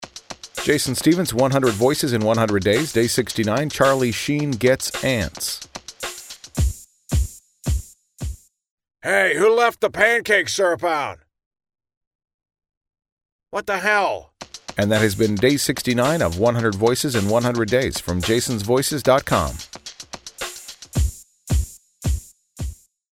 For today’s episode, I’m using my Charlie Sheen impression – as he realizes he, too, has ants.
Tags: celebrity sound a like, Charlie Sheen impression, voice actor for hire